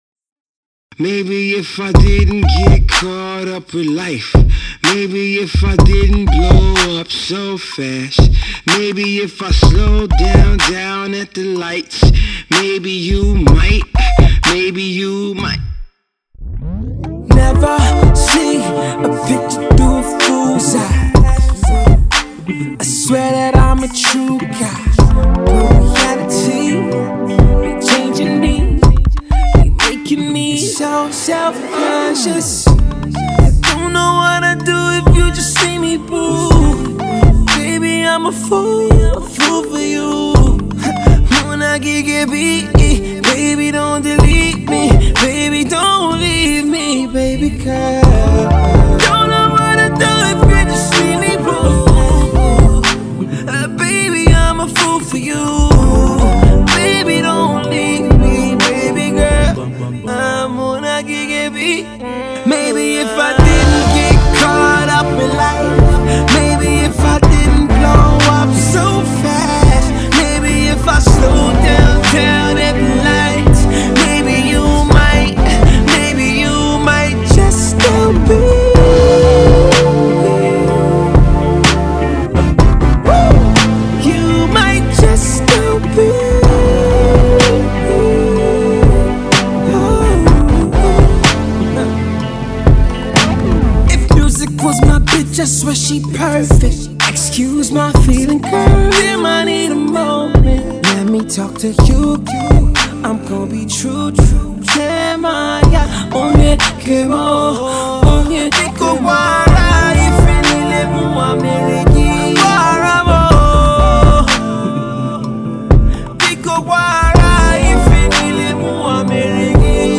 Nigerian R&B singer
is back to stay with a smooth single titled